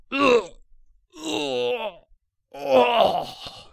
人声采集素材/男3战士型/ZS死亡2.wav